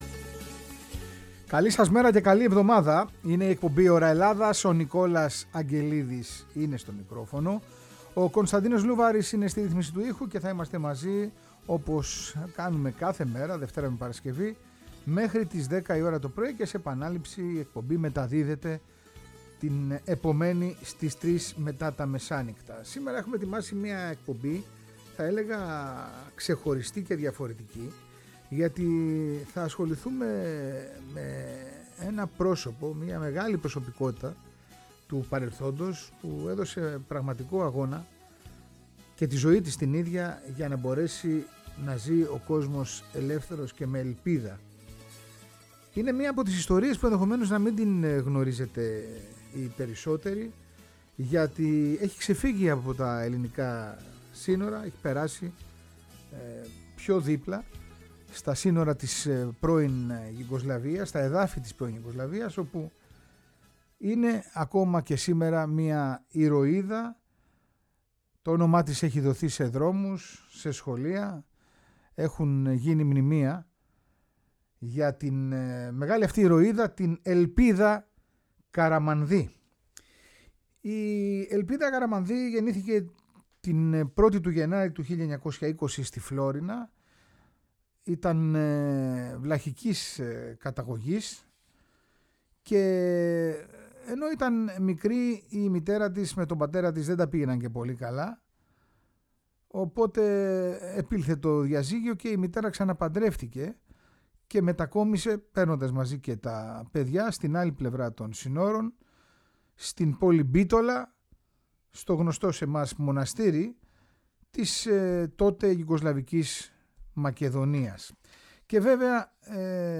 Ντοκιμαντέρ